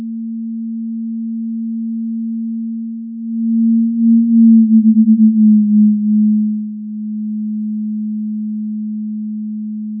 Repeat part 02, but assume that the observer is a human listening binaurally. In effect, generate a stereo output, as if there were 2 observers at a user-defined distance away from the sound source trajectory, at a certain distance apart from each other.